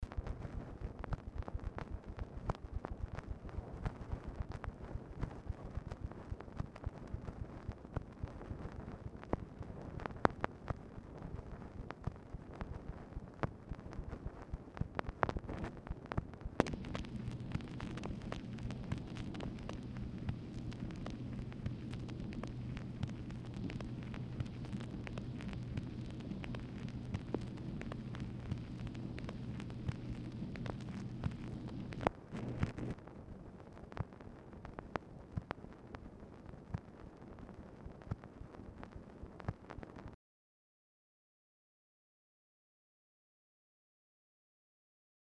Telephone conversation # 10537, sound recording, MACHINE NOISE, 8/2/1966, time unknown | Discover LBJ
Dictation belt